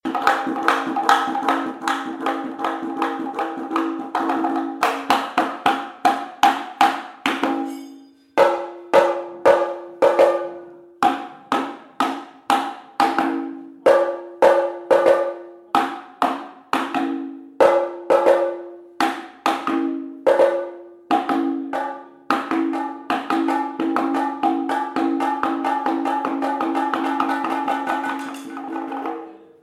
keraladrums1.mp3